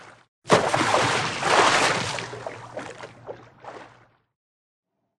anchordown.ogg